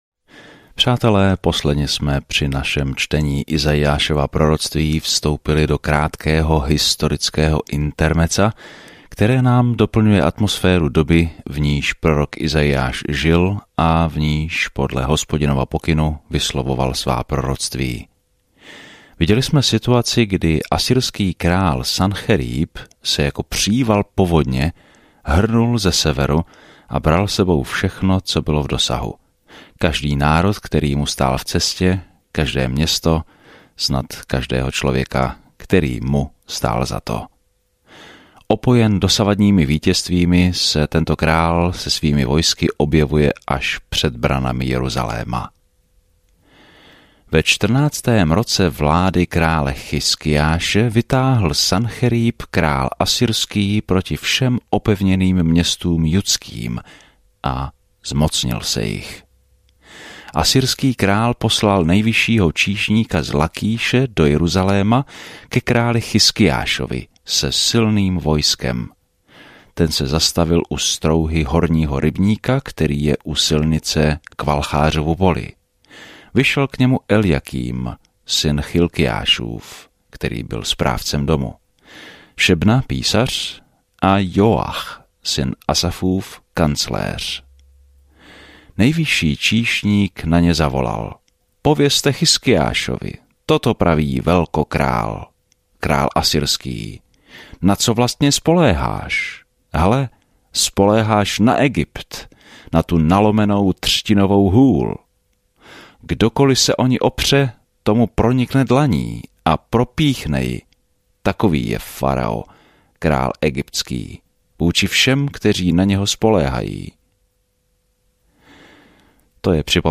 Písmo Izaiáš 36:10-22 Izaiáš 37:1-14 Den 40 Začít tento plán Den 42 O tomto plánu Izajáš, nazývaný „páté evangelium“, popisuje přicházejícího krále a služebníka, který „ponese hříchy mnohých“ v temné době, kdy Judu dostihnou političtí nepřátelé. Denně procházejte Izajášem a poslouchejte audiostudii a čtěte vybrané verše z Božího slova.